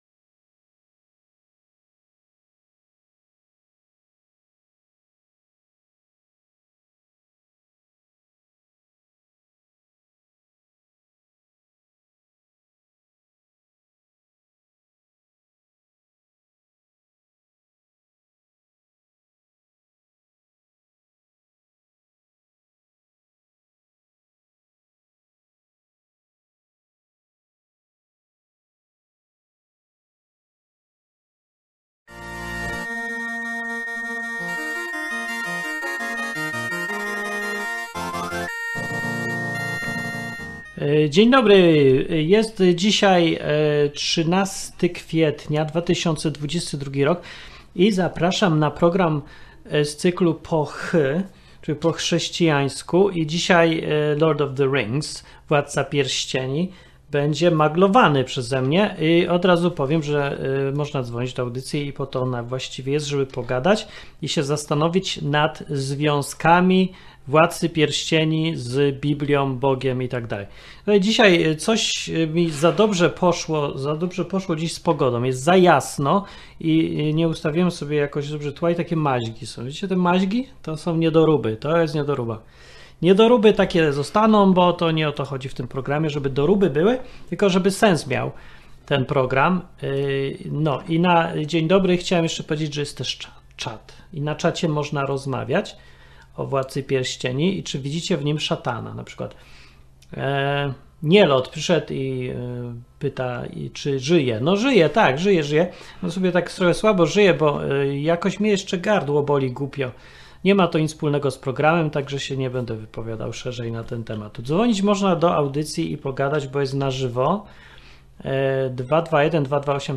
"Po chrześcijańsku" to rozmowy o filmach, książkach, muzyce i kulturze z perspektywy chrześcijańskiej. Program jest na żywo, więc można pisać, komentować i nawet zadzwonić.